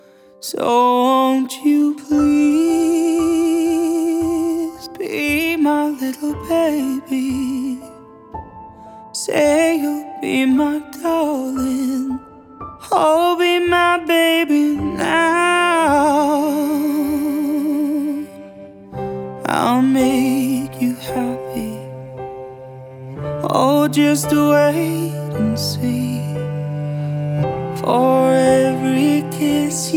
Singer Songwriter Pop
Жанр: Поп музыка